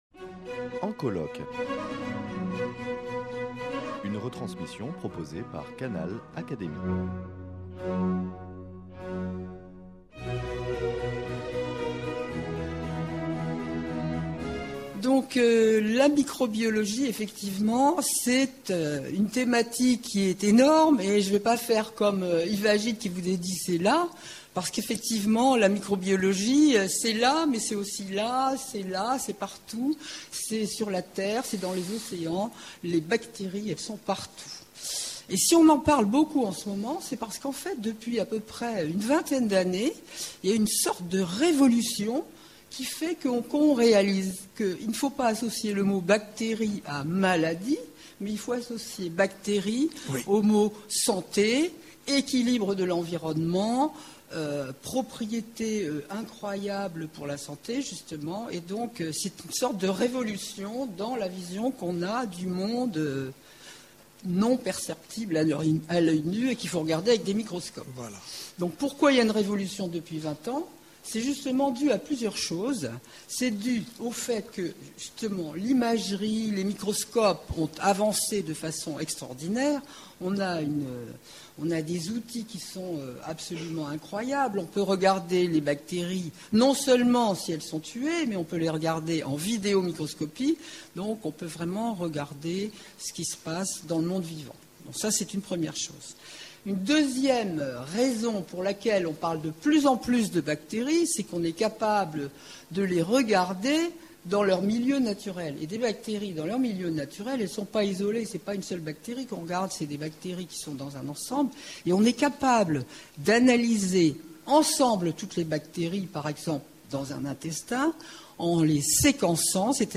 Rencontres animées par François d’Orcival, (Académie des sciences morales et politiques), organisées à la Fondation Dosne-Thiers à l’occasion des journées du patrimoine 2018.